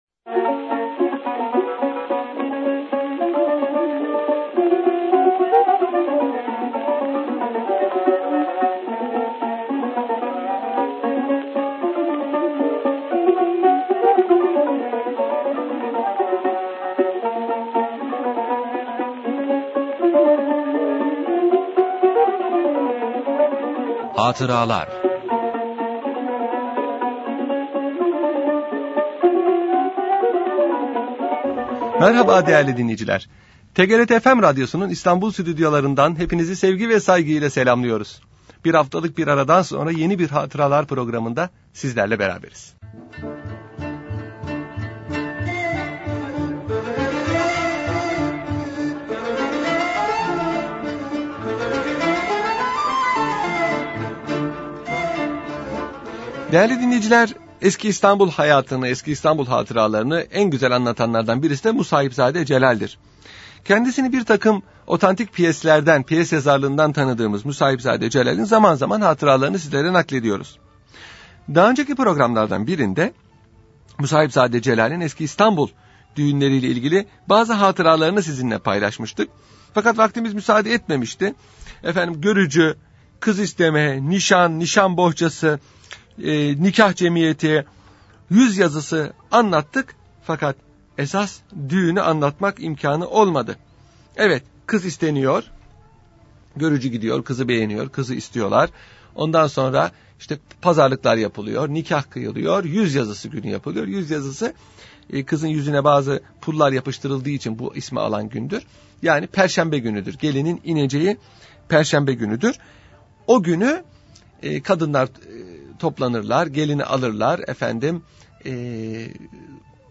Radyo Programi - Musahibzade-Doğum Adetleri